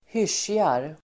Ladda ner uttalet
Uttal: [²h'ysj:jar el. ²h'ys:ar]